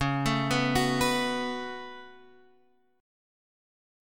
C#7sus4#5 chord